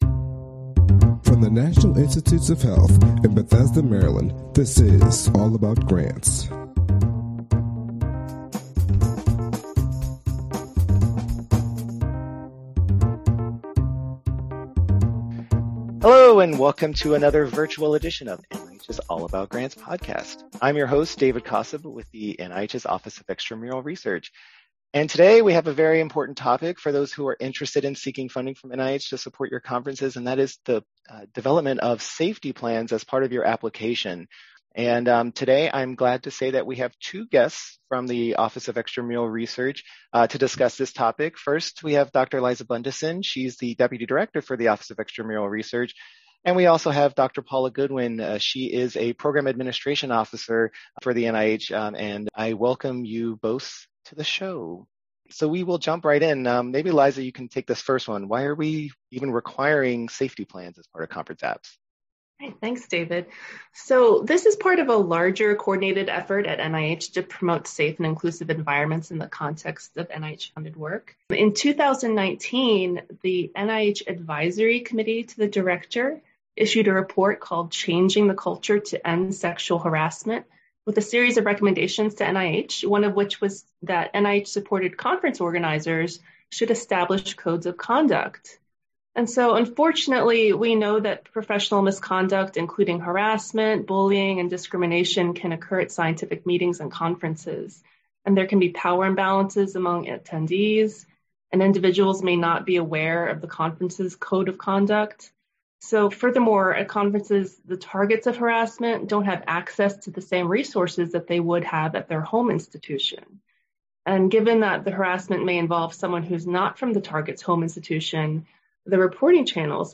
The Office of Extramural Research (OER) at the National Institutes of Health (NIH) presents conversations with NIH staff members. Designed for investigators, fellows, students, research administrators, and others, we provide insights on grant topics from those who live and breathe the information.